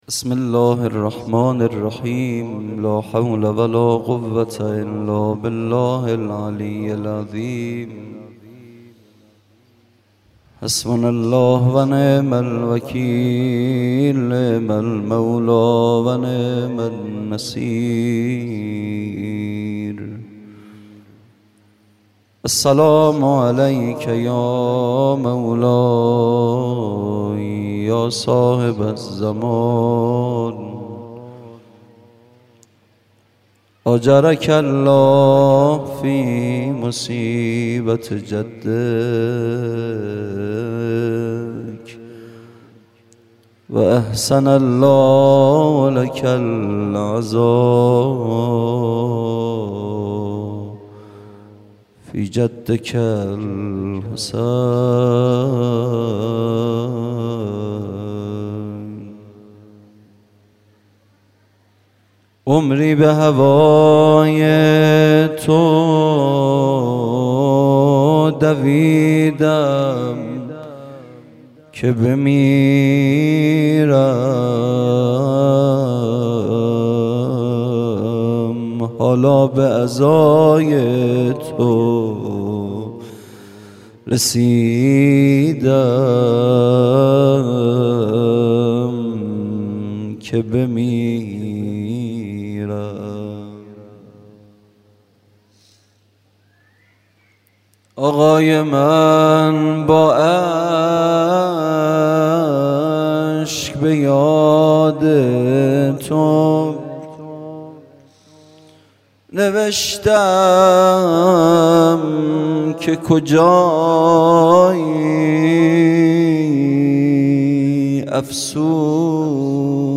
خیمه گاه - هیئت بچه های فاطمه (س) - سخنرانی | پنج شنبه ۲۸ مرداد ۱۴۰۰
دهه اول محرم الحرام ۱۴۴۳ | روز عاشورا